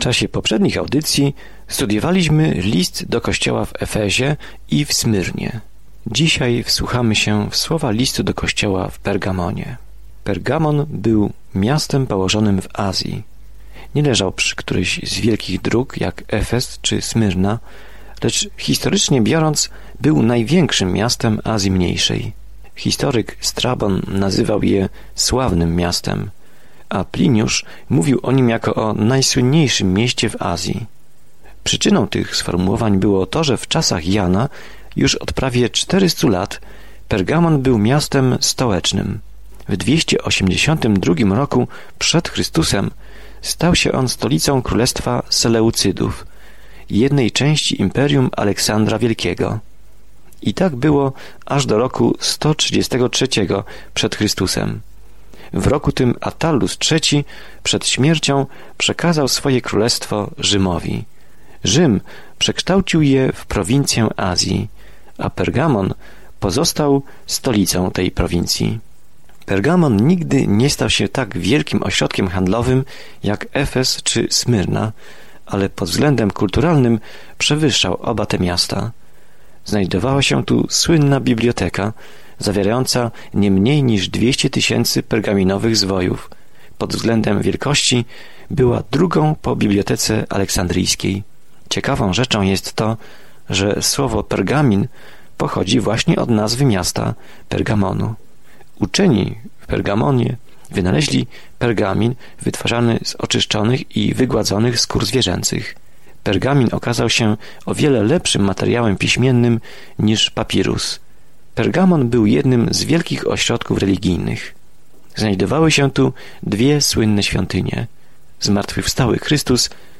Scripture Revelation 2:12-17 Day 7 Start this Plan Day 9 About this Plan Apokalipsa św. Jana opisuje koniec rozległego planu dziejów, przedstawiając obraz tego, jak zło zostanie ostatecznie uporane, a Pan Jezus Chrystus będzie rządził z całą władzą, mocą, pięknem i chwałą. Codziennie podróżuj przez Objawienie, słuchając studium audio i czytając wybrane wersety słowa Bożego.